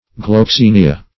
Gloxinia \Glox*in"i*a\, n. [NL.] (Bot.)